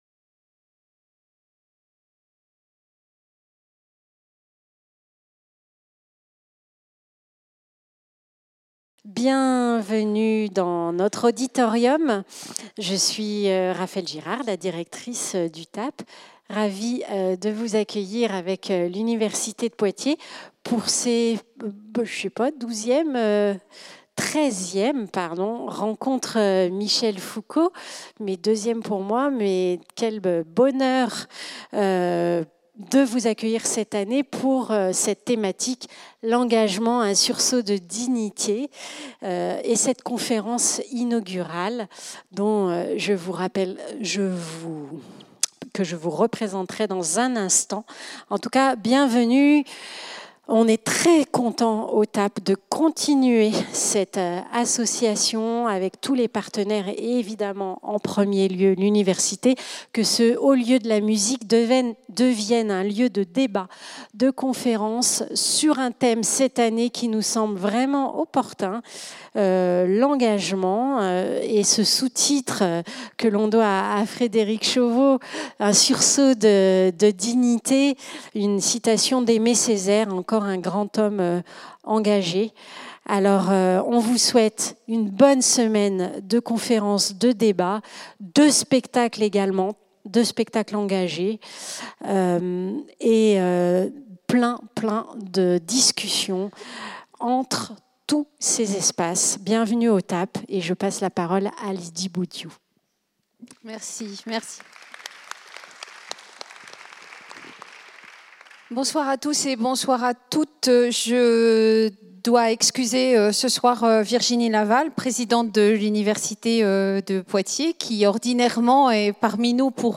Le Règne des passions Conférence de Christophe Bourseiller